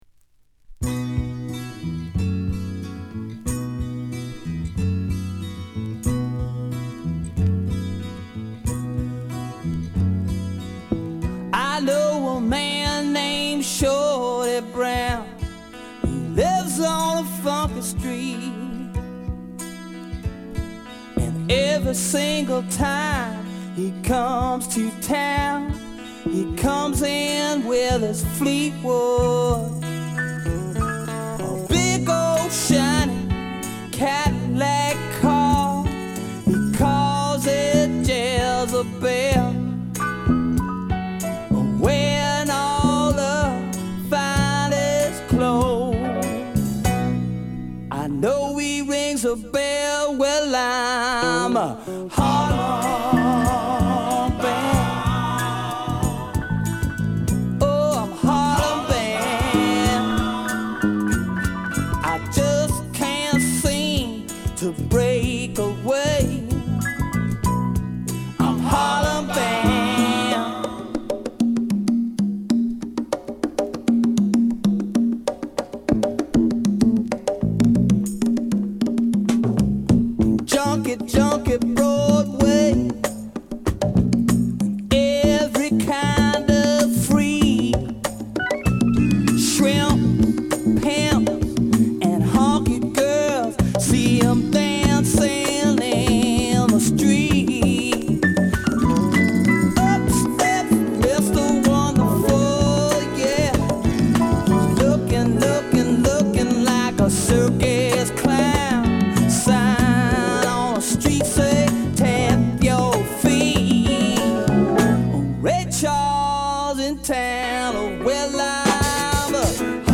セクシャルな歌声がたまらないブルージーなフォーク・ロック〜レゲエナンバーまで最高！
Blues Rock , Folk , Folk Rock
Mellow Groove